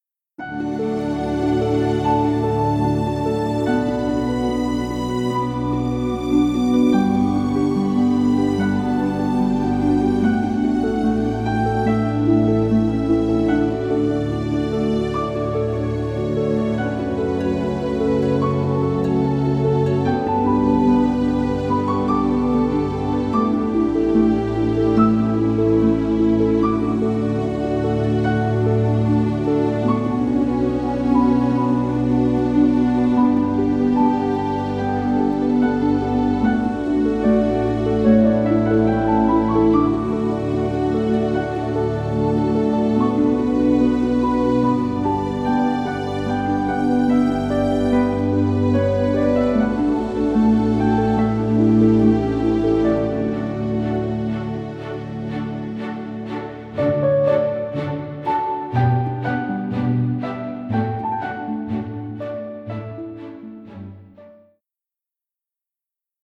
sensitive and gentle score
gentle transparency and full orchestra-like ambience